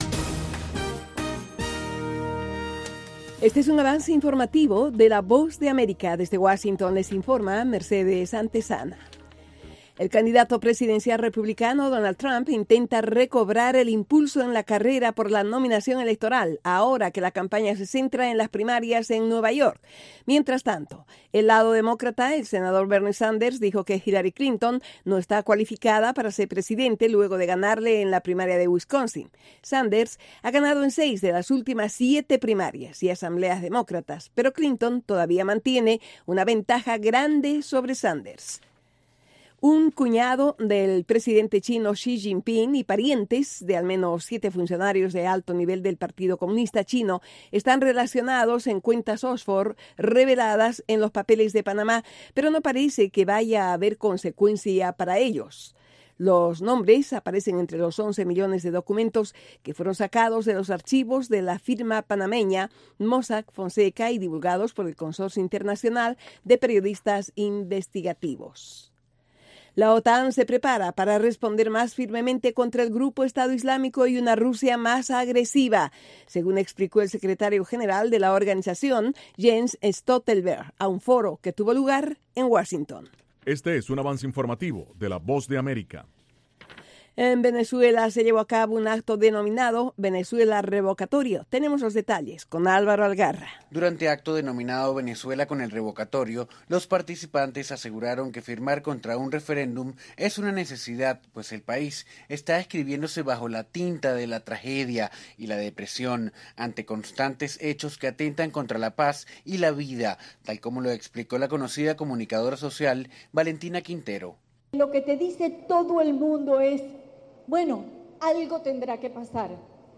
Capsula informativa de tres minutos con el acontecer noticioso de Estados Unidos y el mundo.